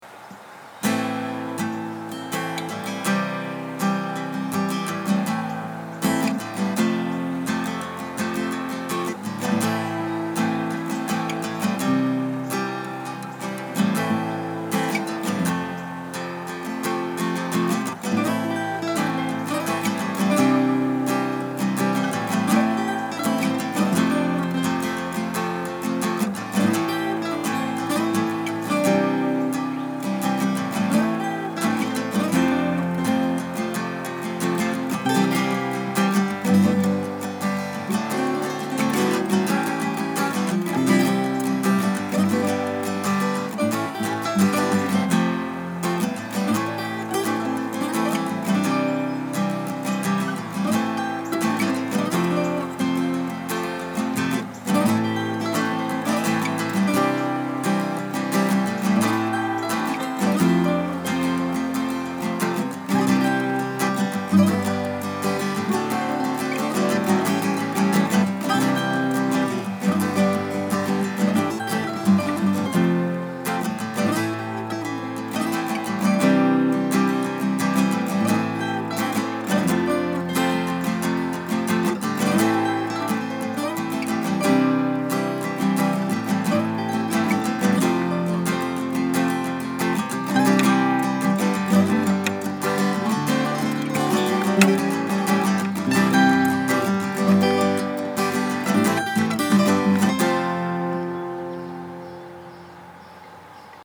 Four guitar tracks.